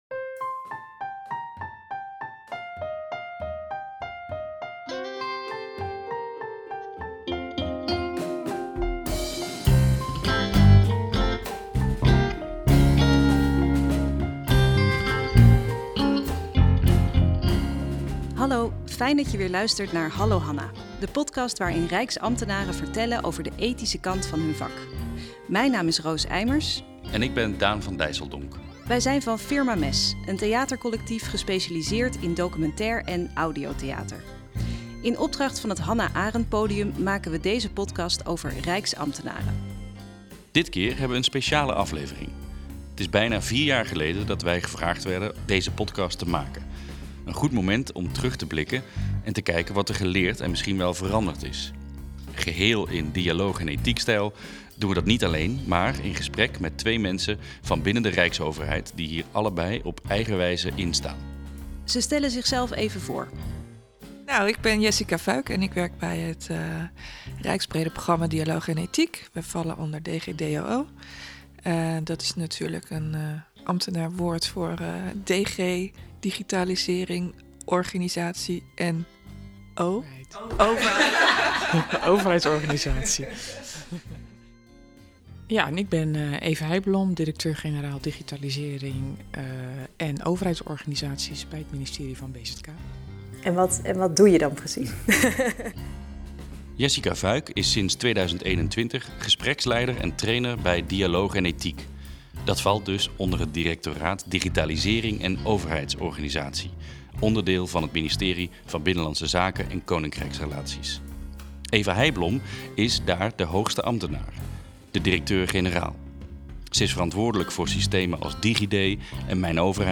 In de podcast vertellen Rijksambtenaren over situaties die zij als ingewikkeld of bepalend hebben ervaren. Hun verhalen worden geplaatst in de context van het werk van Hannah Arendt over denken, handelen en oordelen.